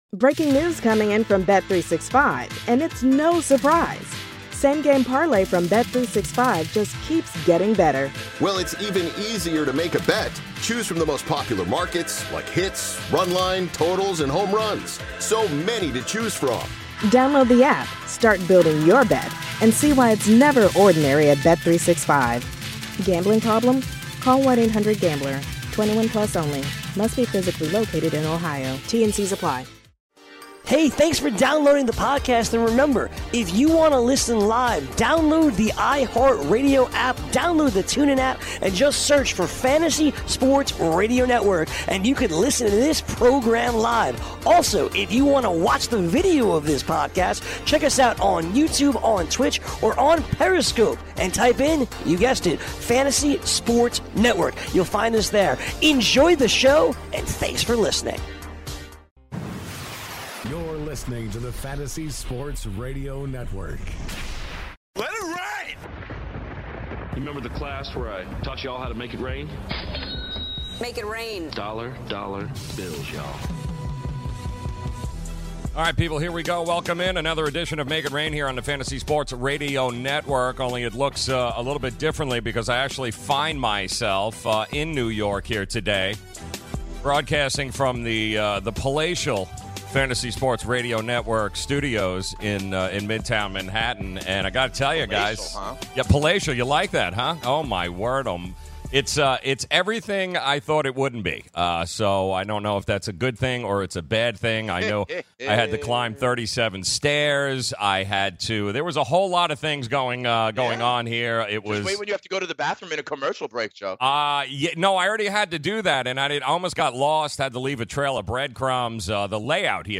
is LIVE in New York City